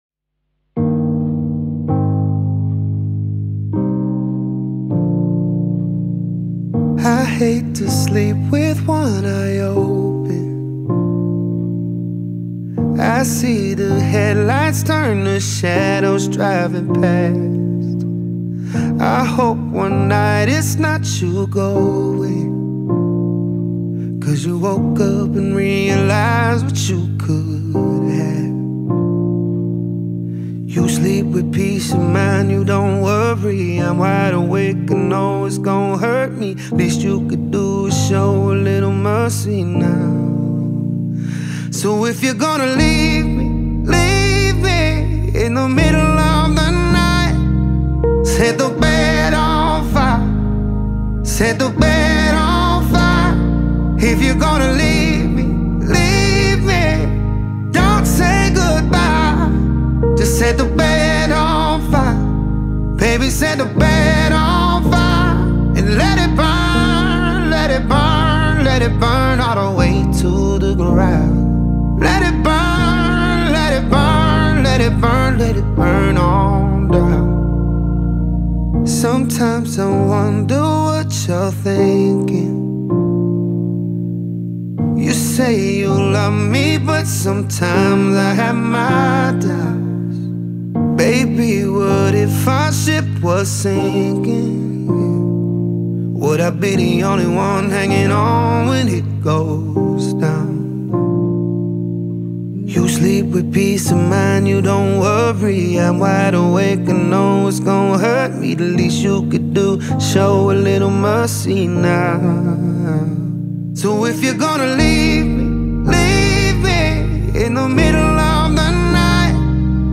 глубокую эмоциональную балладу